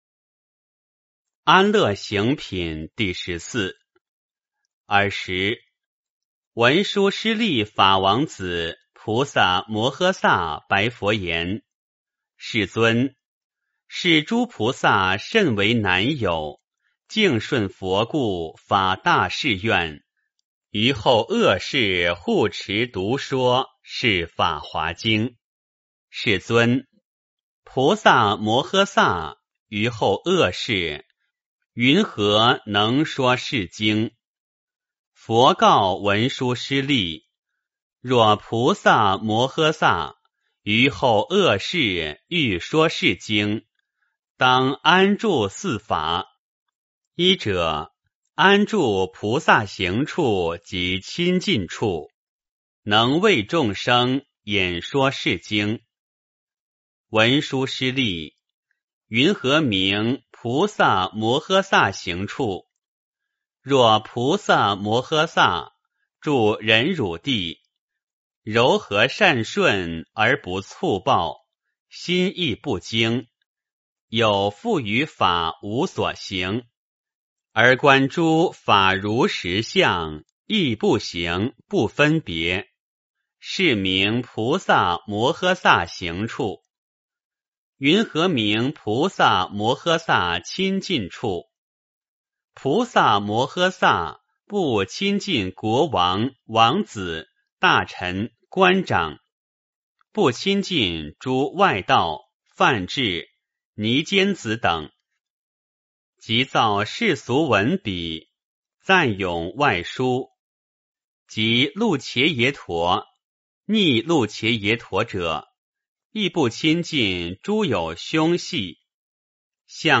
法华经-安乐行品第十四 - 诵经 - 云佛论坛